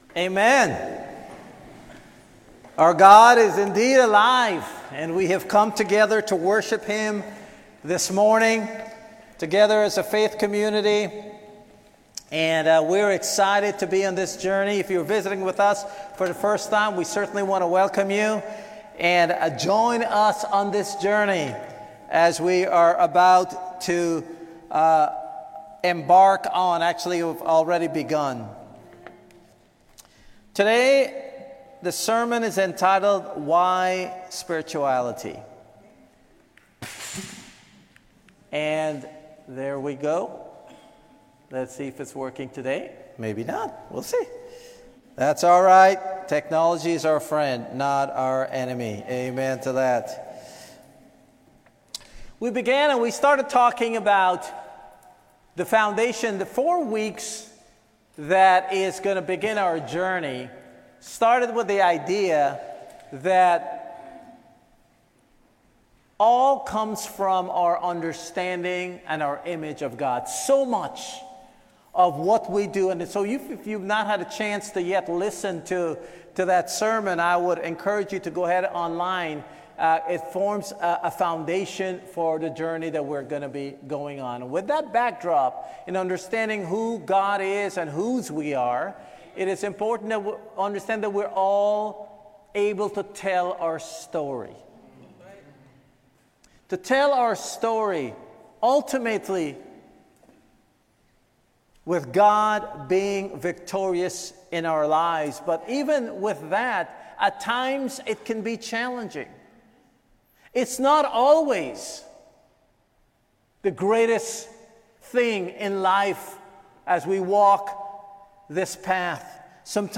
In this lesson, we examine some of the distinct features of your spirituality (how you live out your faith).